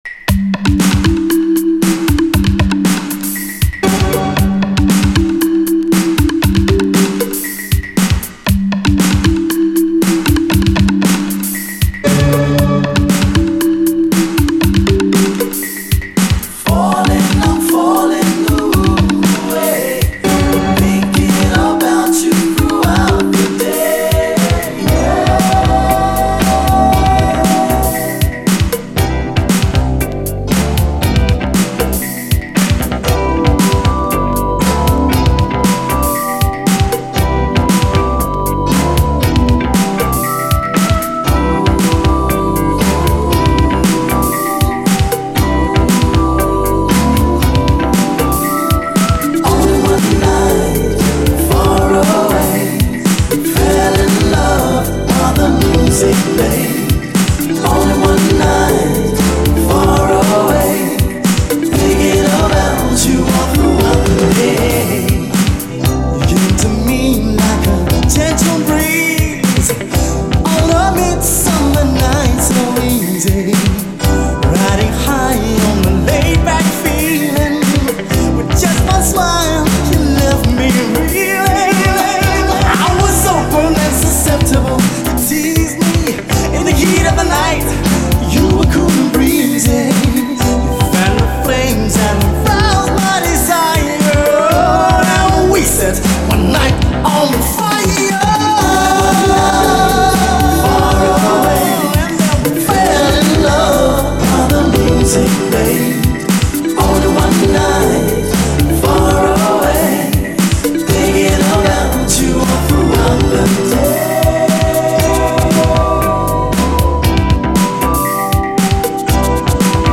SOUL, 70's～ SOUL, DISCO
爽快なUK産バレアリック・メロウ・シンセ・ブギー！